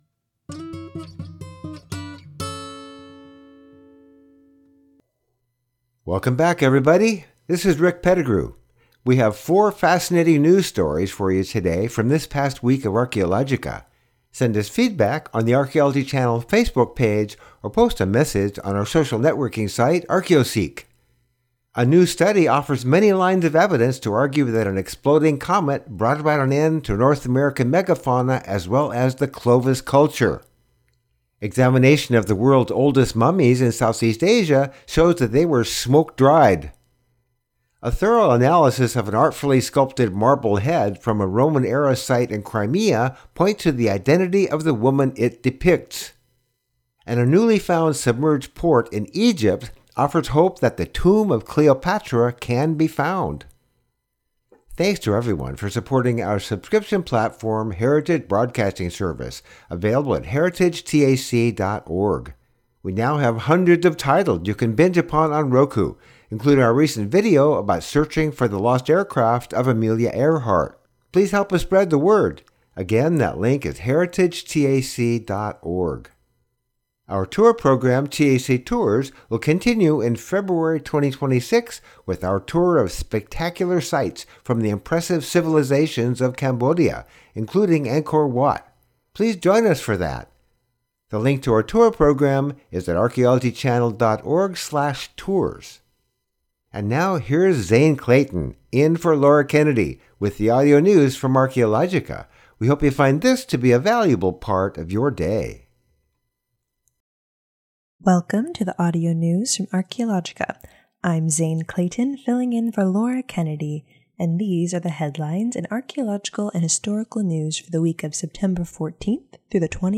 The Archaeology Channel - Audio News from Archaeologica 133 subscribers updated 5d ago Subscribe Subscribed Play Playing Share Mark all (un)played …